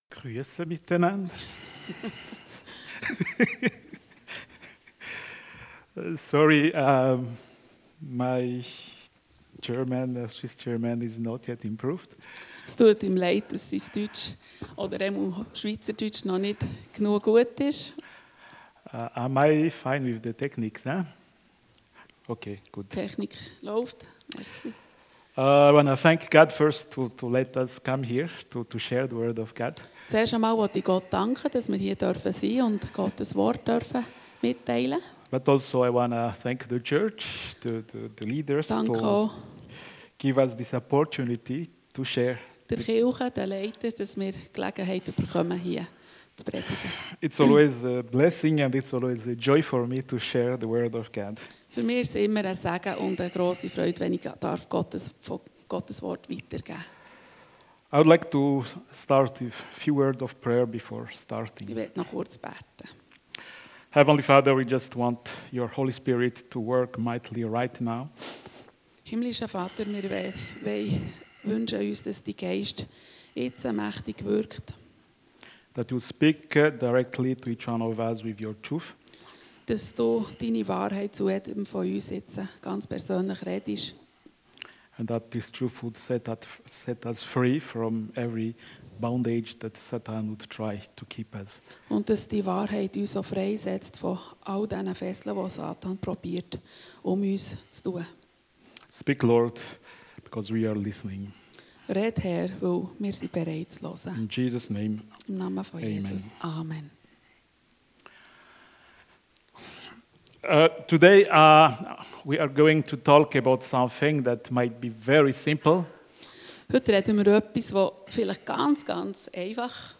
Missions-Gottesdienst